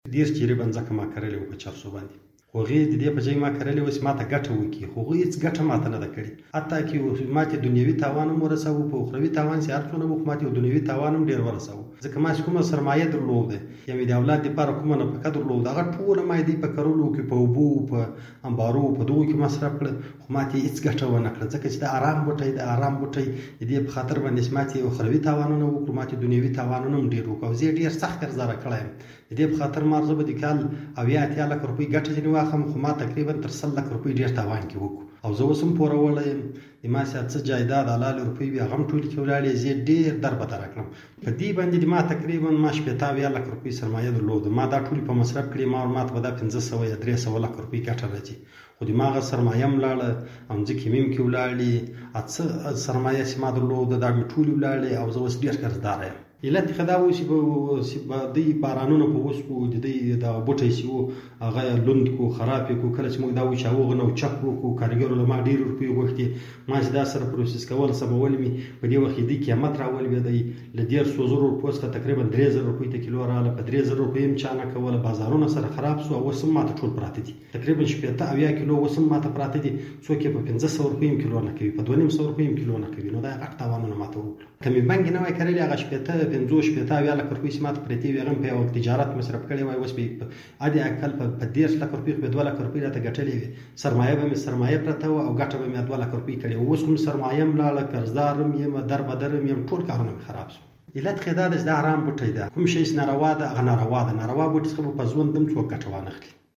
دا به خپله د ده له خولې واورئ: